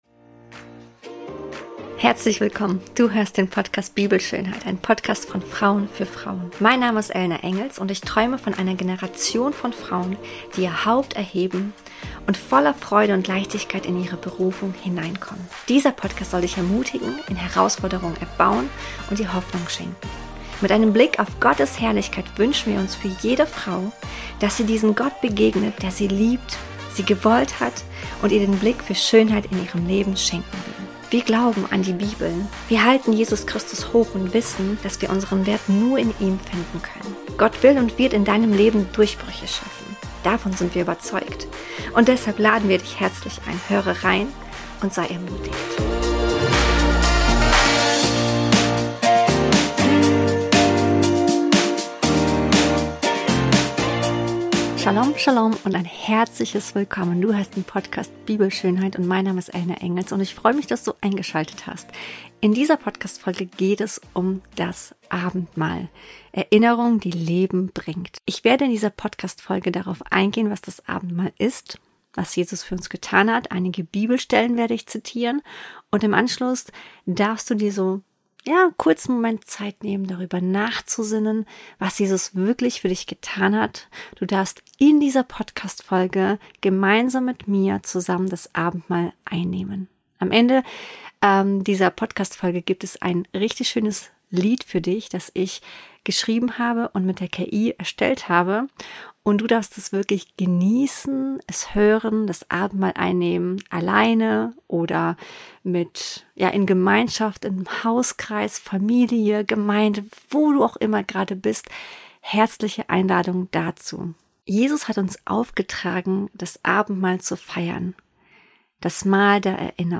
Diese Episode lädt dich ein, zur Ruhe zu kommen und dich neu auf das Zentrum auszurichten. Der Song am Ende dieser Folge ist aus einem Moment der Erinnerung entstanden.